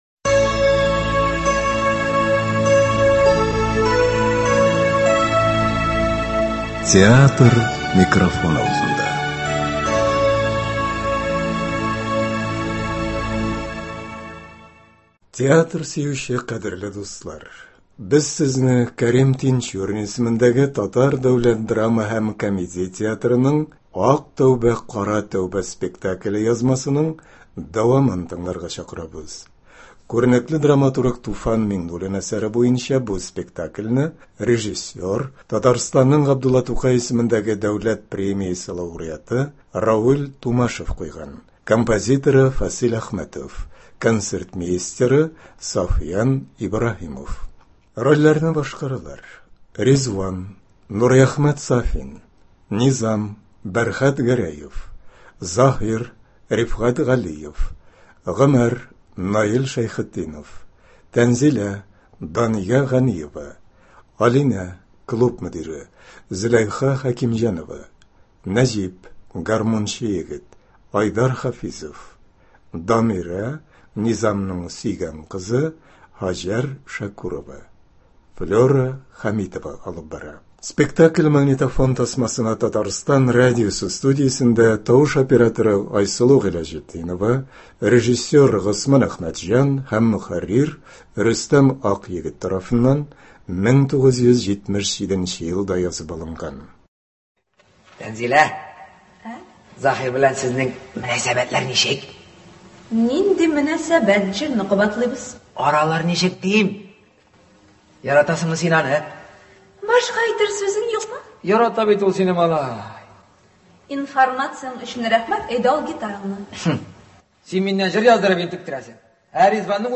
ТДДһК театры спектакле.